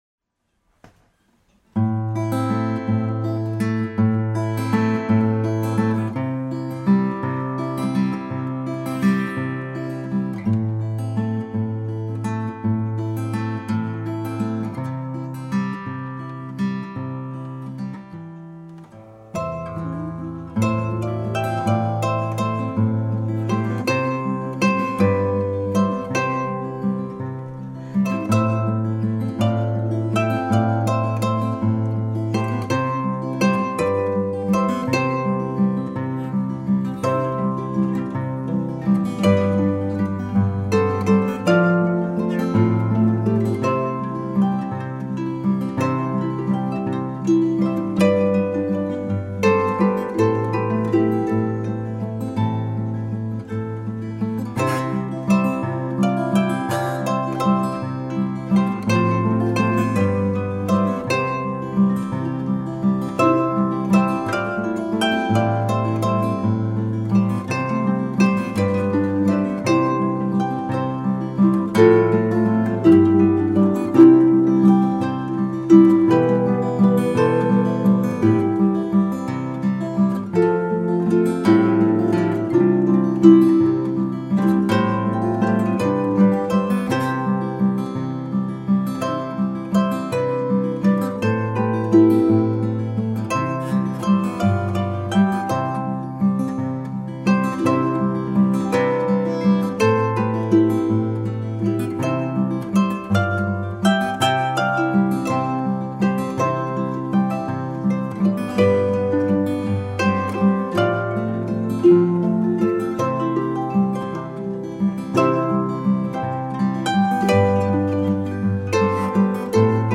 Harp Music